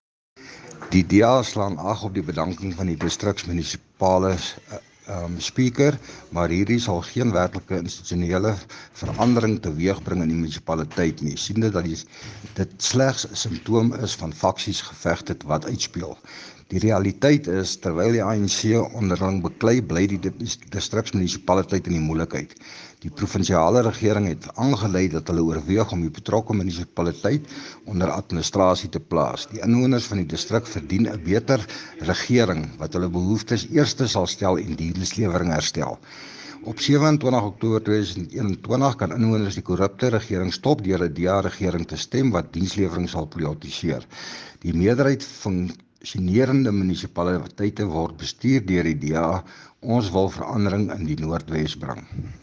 Afrikaans deur DA-raadslid, Dr Ruth Segomotsi Mompati Distriksmunisipaliteit, Jan Brand.
Jan-Brand-Afrikaans-Voice-Note.mp3